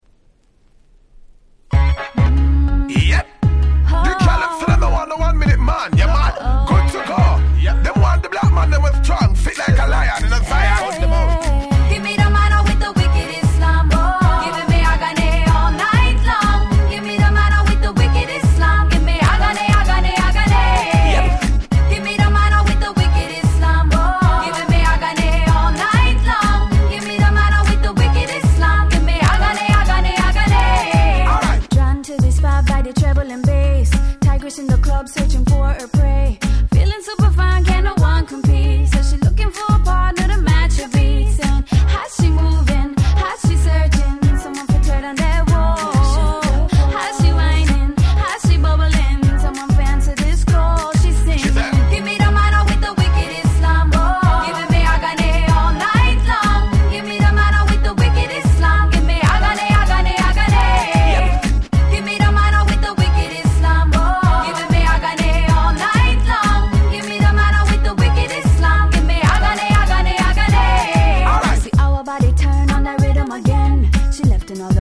04' Nice Dancehall R&B !!
キャッチーなDancehall Reggae寄りのR&Bをお探しの方にはドンピシャなはず！